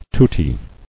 [tut·ti]